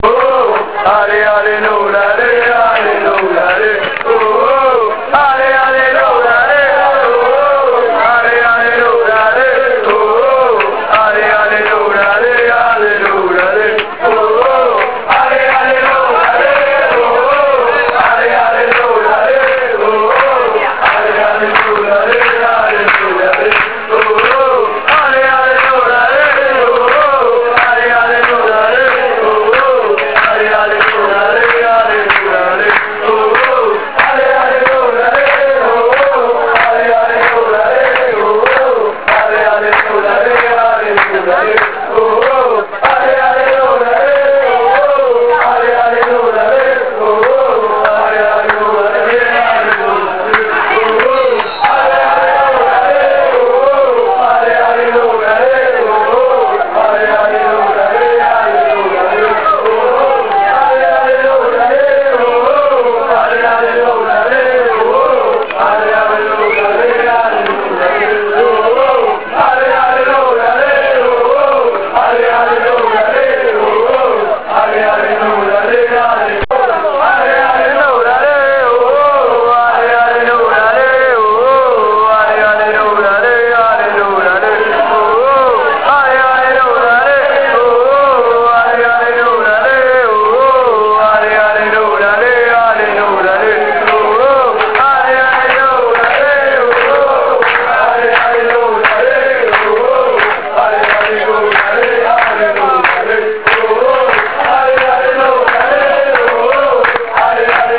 Ultras Novoli 06-07 - cori